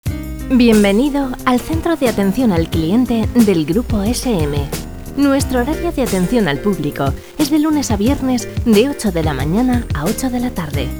mensaje de centralita
Música sin copyright de Escena Digital